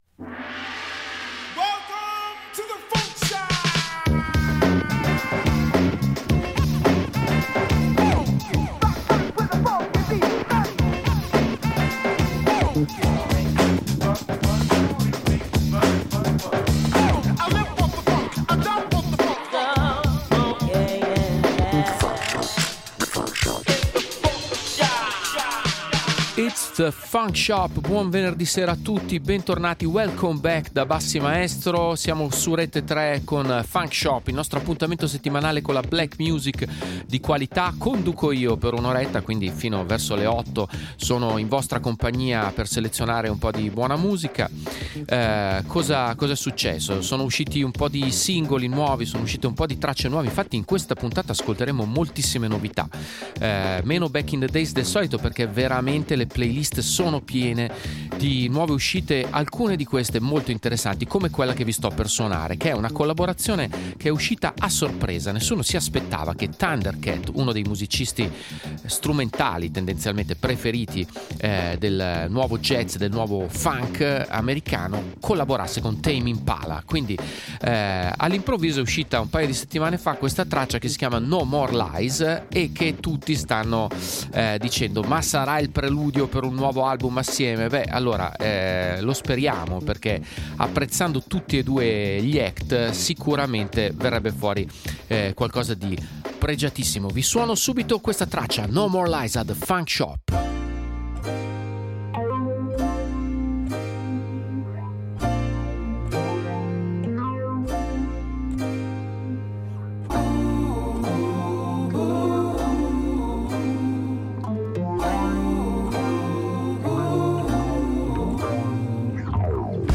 BLACK RAP / HIP-HOP